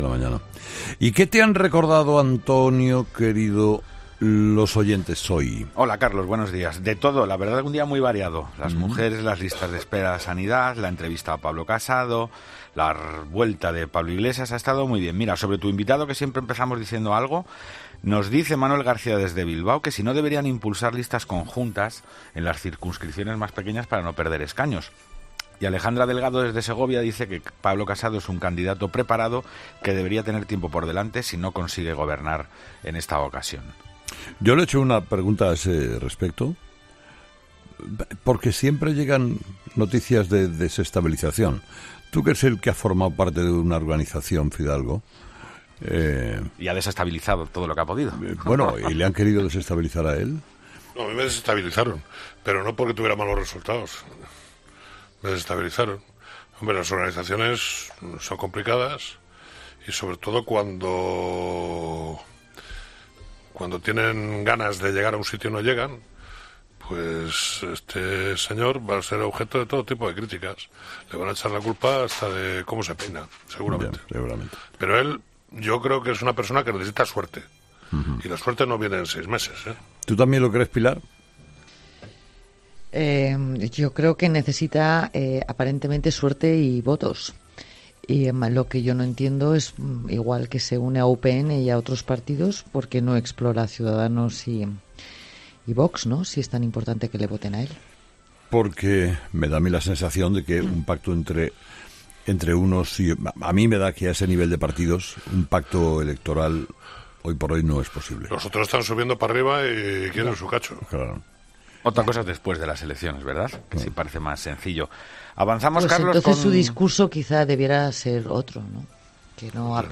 Principales temas que han copado La Tertulia de los Oyentes, el espacio de participación de Herrera en Cope.